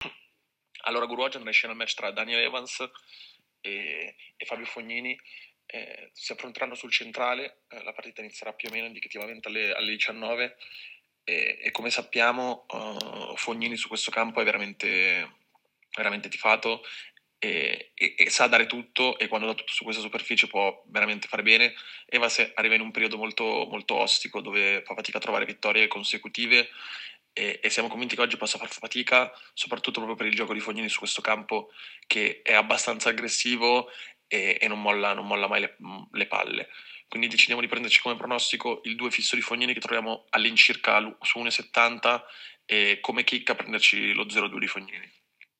è in diretta dal Foro Italico di Roma per darci le migliori scommesse sugli Internazionali di Tennis. In questo audio le sue idee sulla sfida tra Fognini ed Evans.